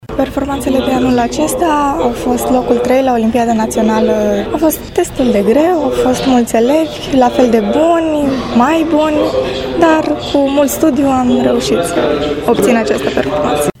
Sala de festivități a Consiliului Județean Brașov a fost plină, iar atmosfera a fost una entuziastă, de final de an, cu ocazia premierii elitelor învățământului brașovean, de către forul județean.